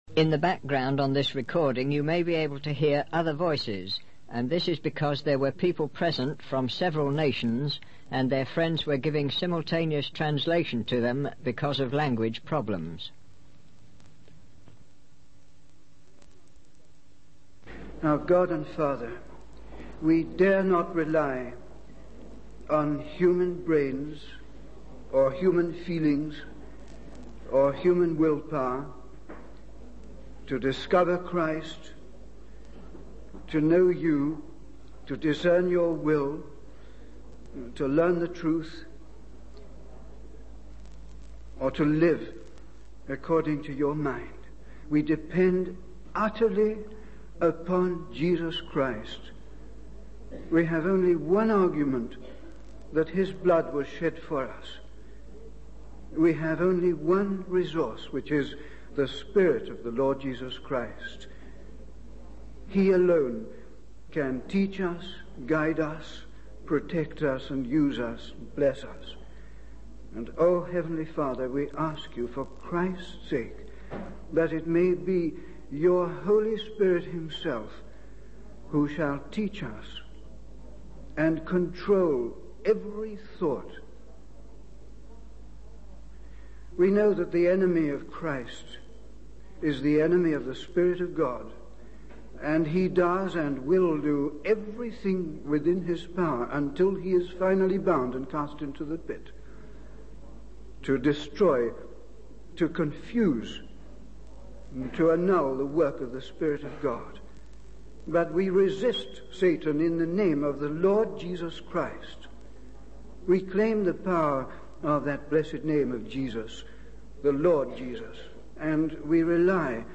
In this sermon, the speaker shares personal experiences that have taught him about human nature and the reliance on God. He discusses difficult moral dilemmas, such as being forced to harm others to save oneself. The speaker emphasizes the need to depend on Jesus Christ rather than human abilities to discover truth and live according to God's will.